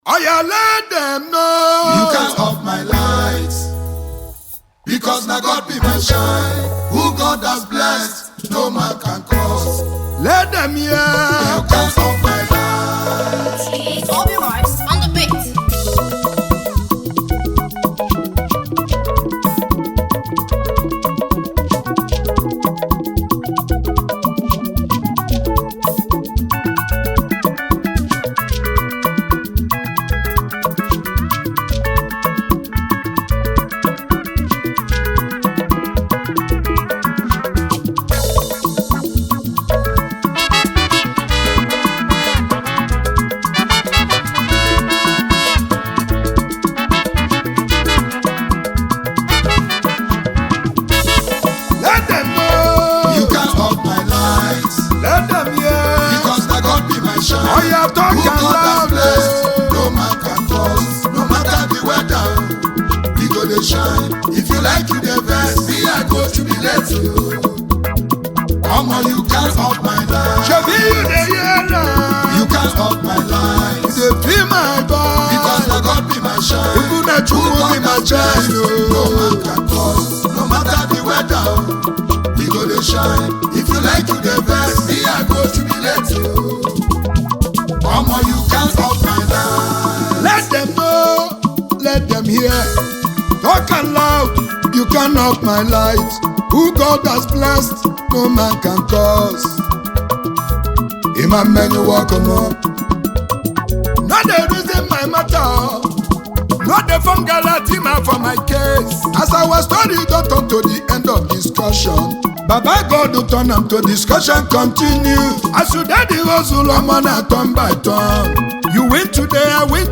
highlife track
a good highlife tune